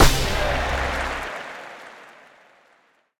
bookOpen.ogg